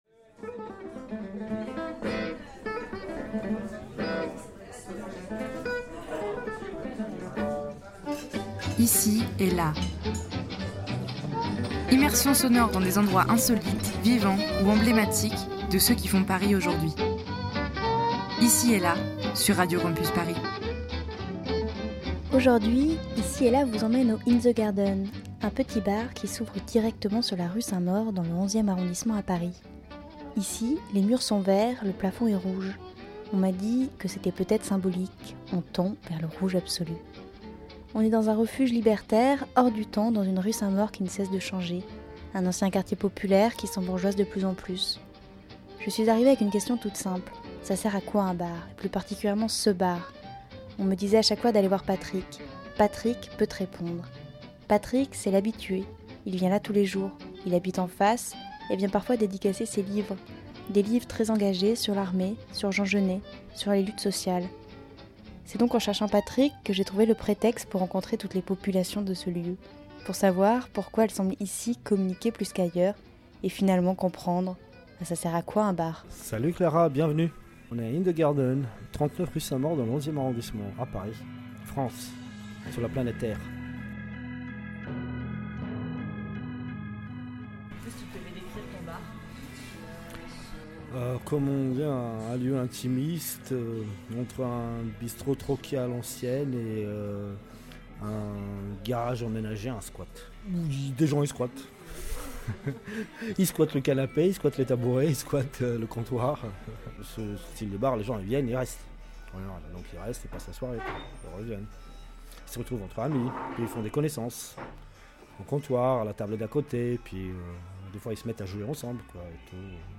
Ici et Là vous emmène cette semaine au "In the garden", un bar qui s'ouvre directement sur la rue saint Maur, dans le 11ème arrondissement...